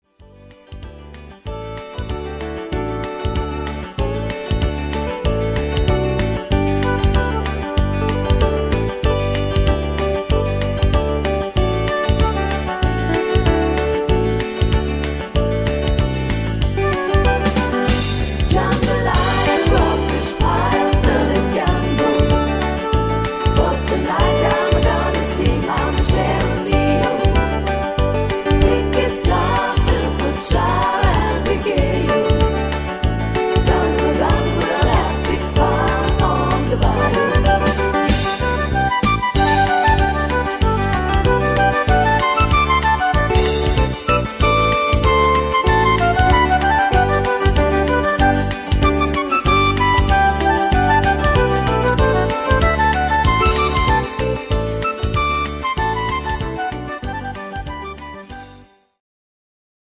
Buy Playback abmischen Buy